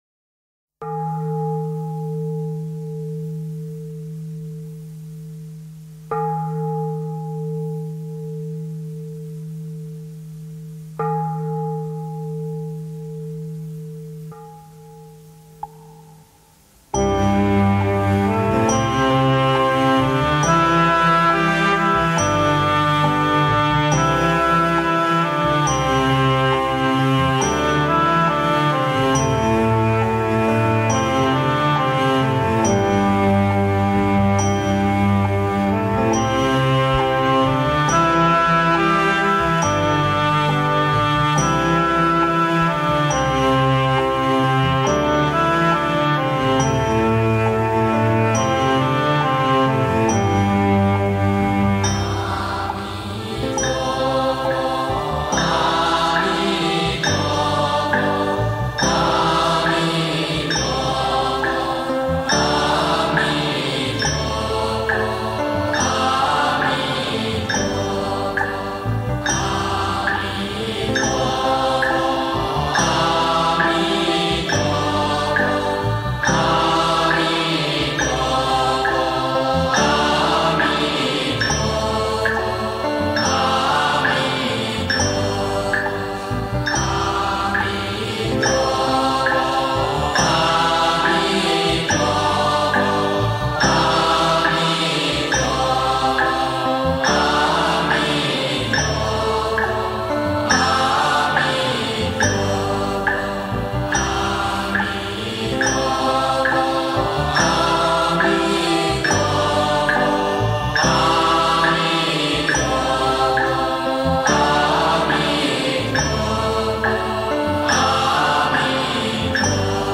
NIỆM PHẬT ĐỊA CHUNG-MP3
niem-phat-dia-chung-(tinh-tong-hoc-hoi)-3967.mp3